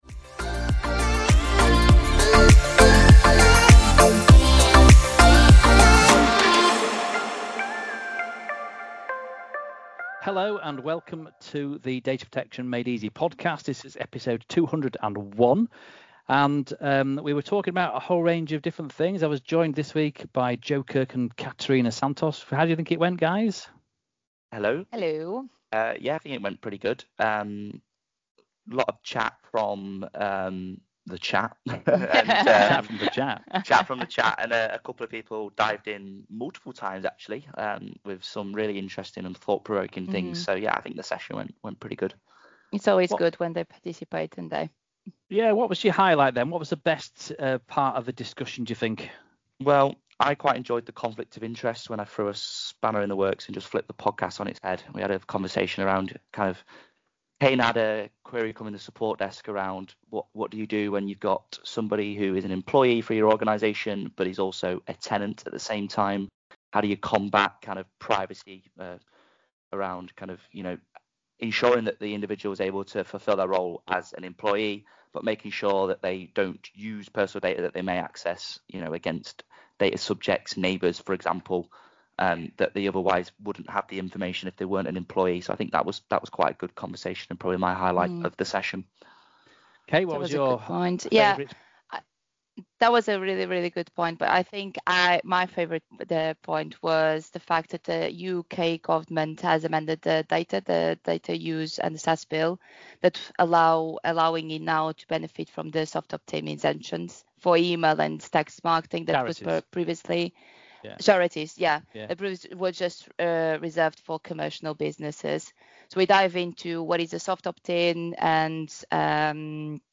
This is our first GDPR Radio session of 2025 and we have a brilliant line up of events in the near future, make sure to check out the Data Protection People website and look out for any future events. Every episode of the Data Protection Made Easy podcast is hosted on Microsoft Teams in front of a live audience.